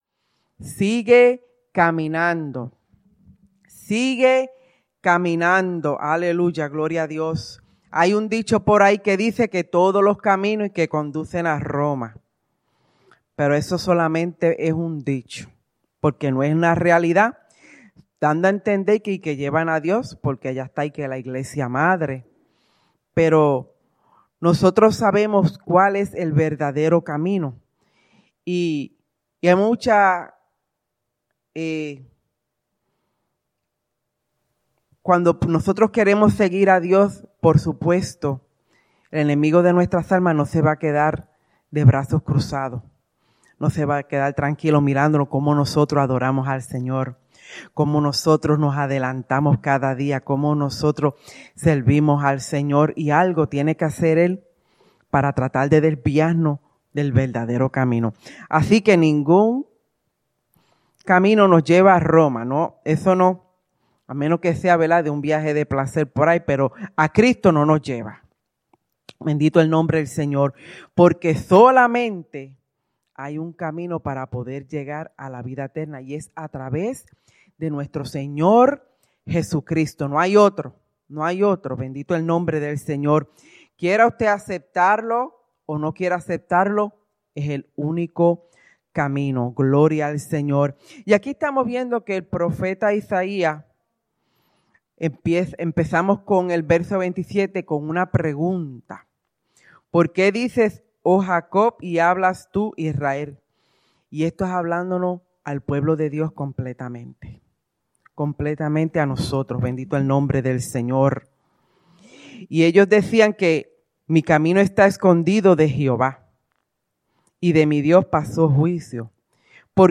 Predica
Souderton, PA